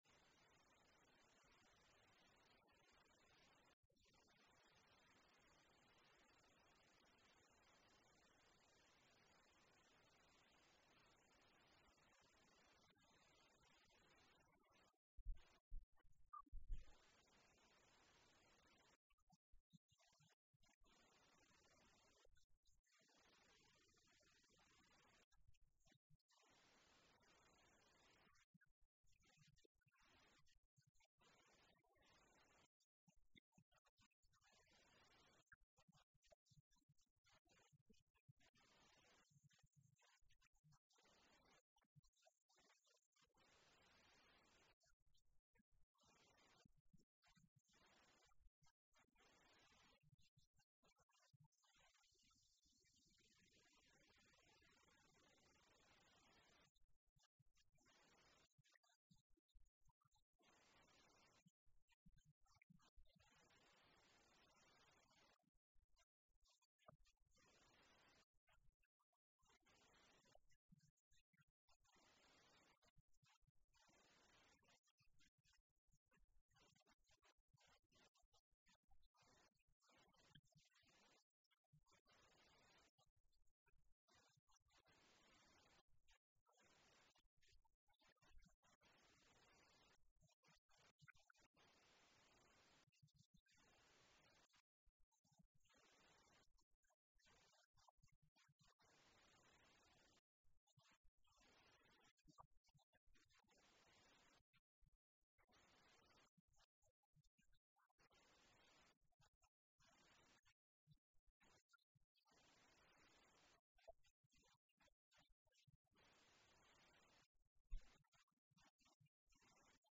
This sermon was given at the Italy 2015 Feast site.